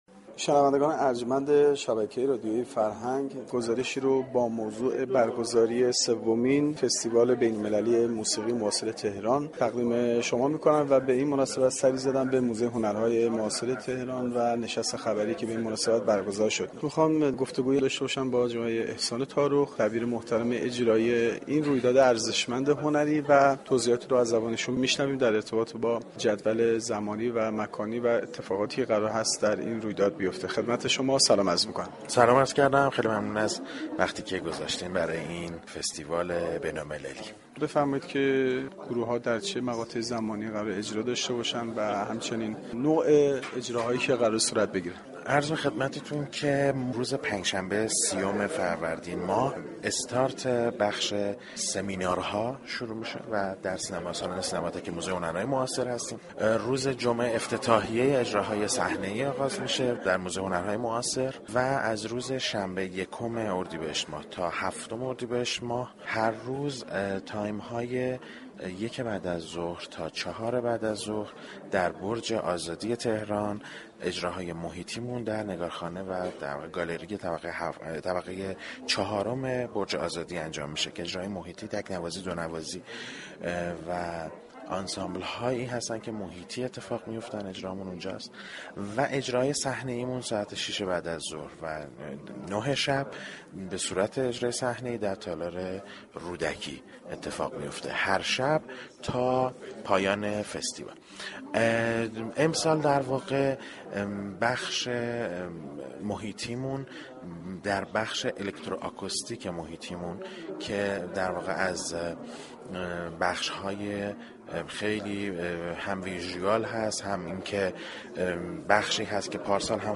گفتگوی اختصاصی